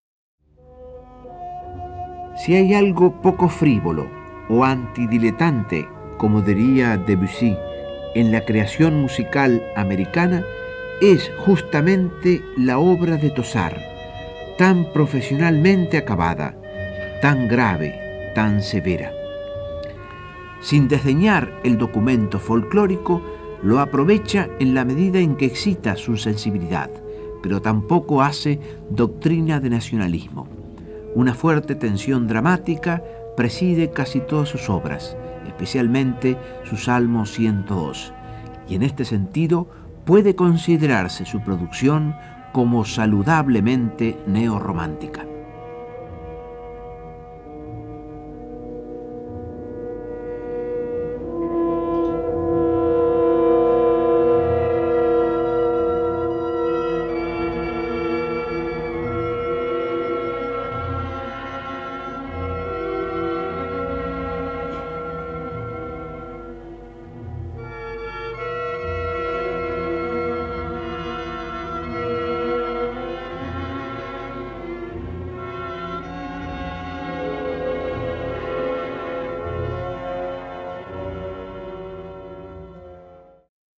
Locución: Lauro Ayestarán